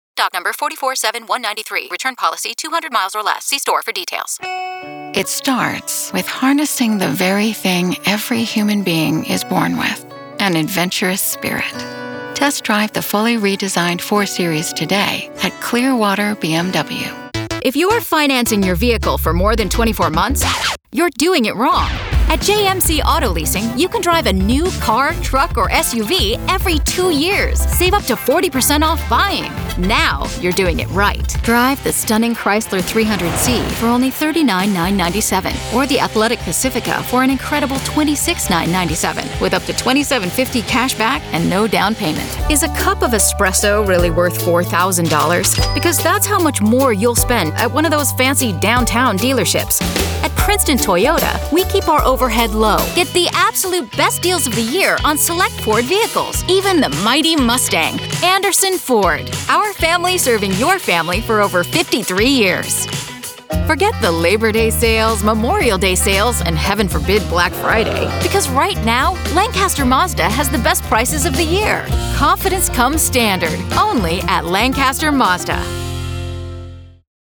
Professional Female Voice Over Talent
Automotive